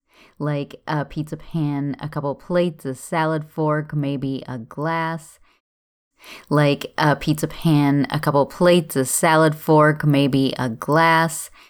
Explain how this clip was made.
Audio critique? It sounds like I'm talking in a box. Your room reverb is not that bad, I’ve heard a lot worse. What you’re saying is clear, but there are audible effects from reflections in the room.